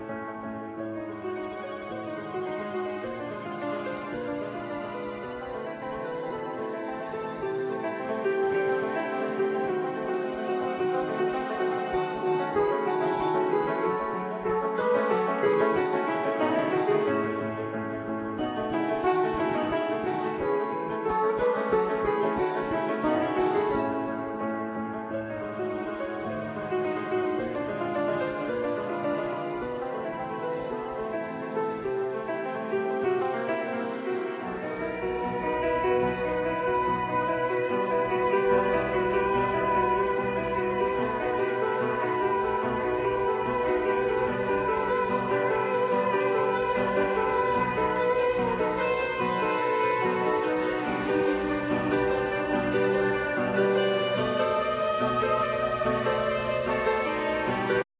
Piano,Synthsizer
El.guitar
Percussions